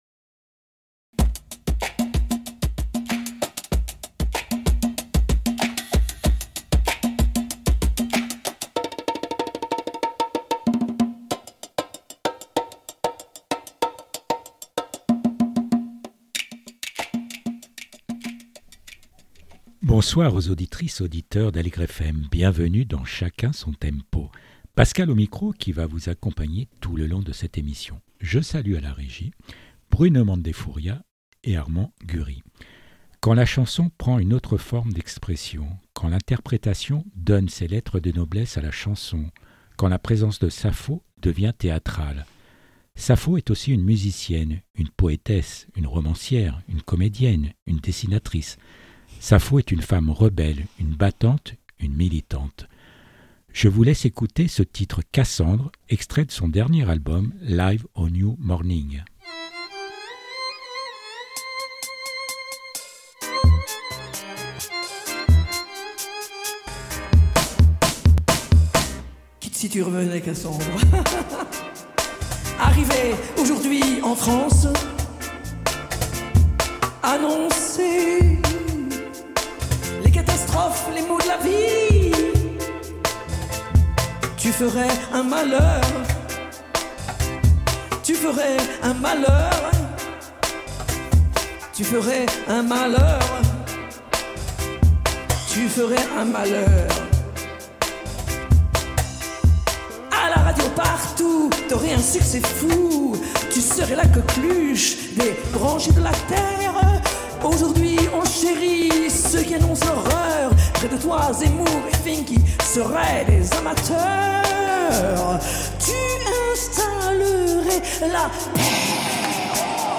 Sapho chez Aligre FM pour la sortie de son dernier album "Live au New Morning"Portrait radiophonique durant lequel Sapho se livre à travers les albums qui ont façonné son parcours.